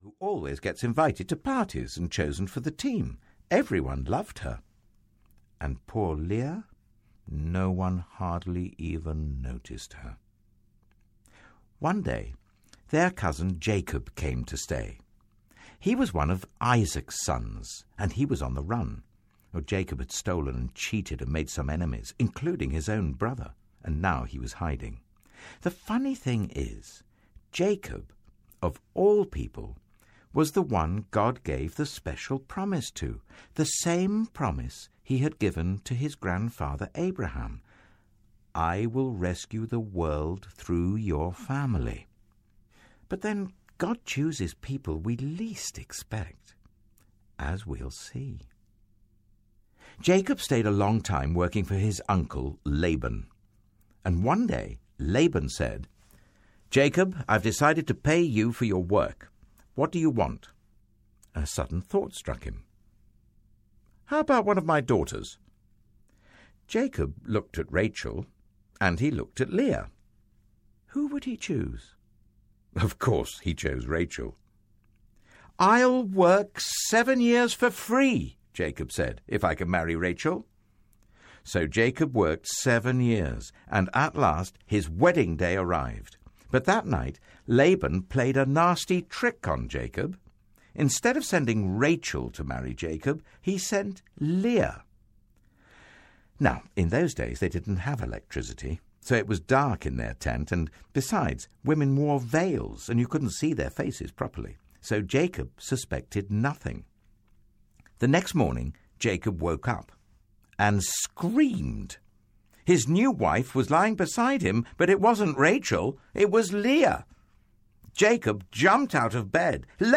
The Story of God’s Love for You Audiobook
Narrator
David Suchet